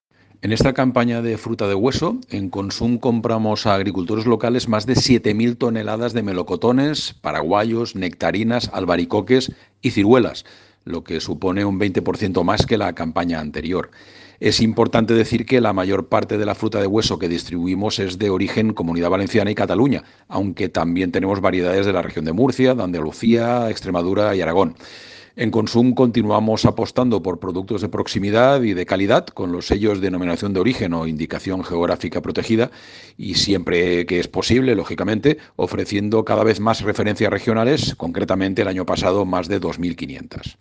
Corte de voz